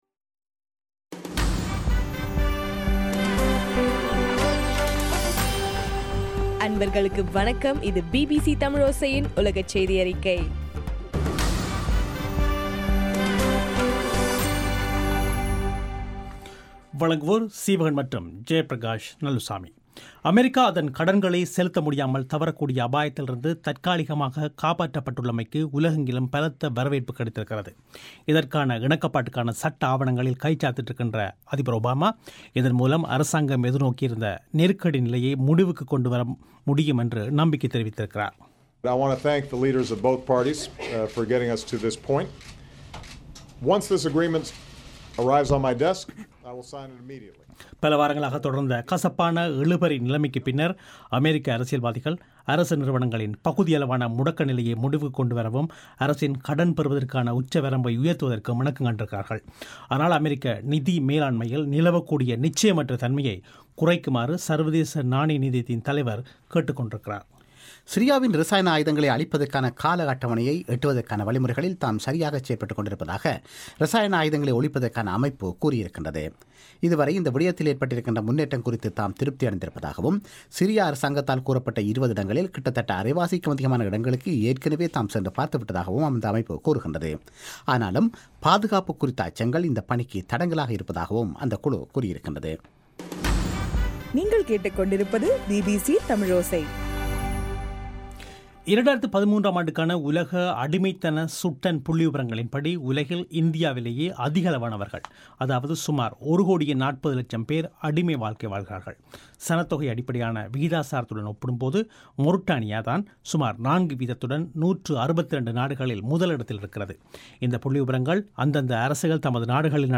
அக்டோபர் 17 2013 பிபிசி தமிழோசை உலகச் செய்திகள்